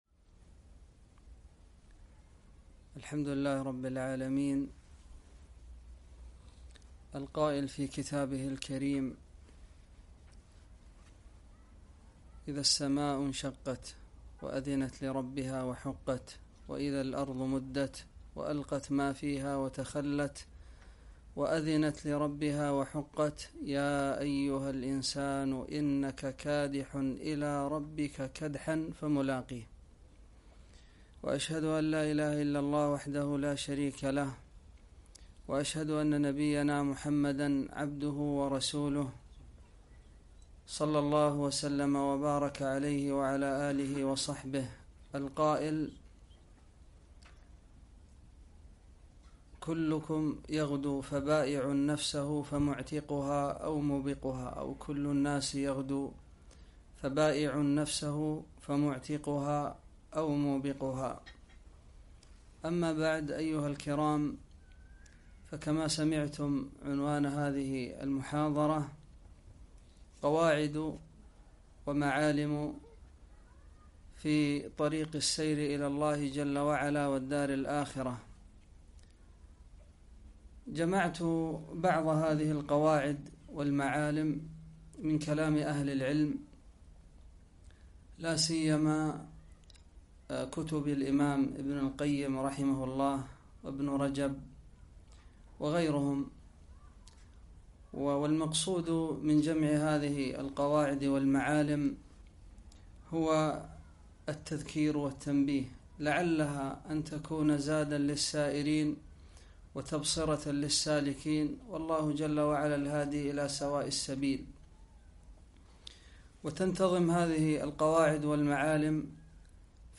محاضرة - قواعد ومعالم في السير إلى الله